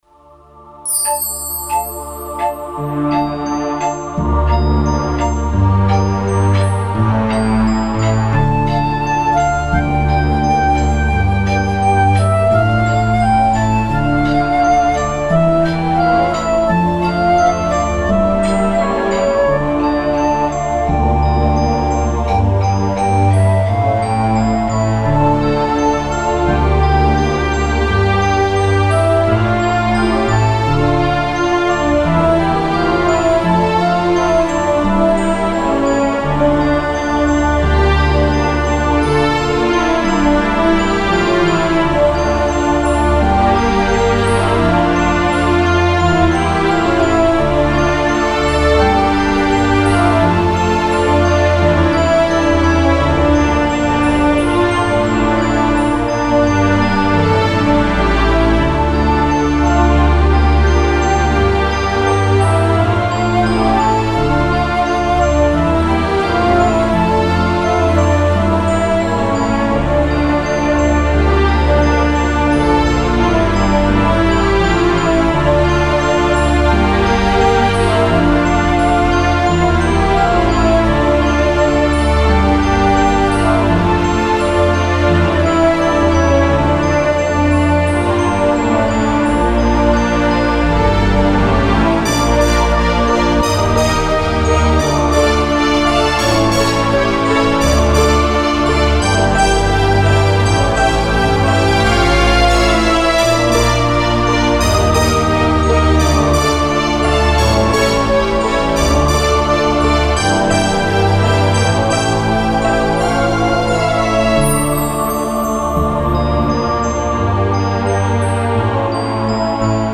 Orquestração